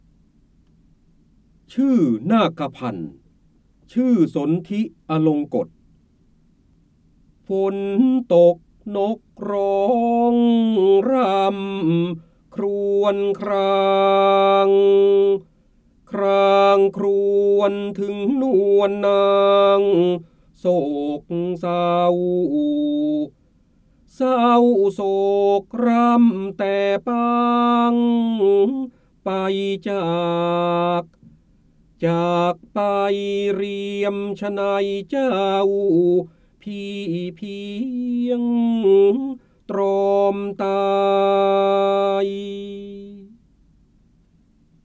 เสียงบรรยายจากหนังสือ จินดามณี (พระโหราธิบดี) ชื่อนาคพันธ์ ชื่อสนธิอลงกฎ
คำสำคัญ : จินดามณี, พระเจ้าบรมโกศ, ร้อยแก้ว, ร้อยกรอง, พระโหราธิบดี, การอ่านออกเสียง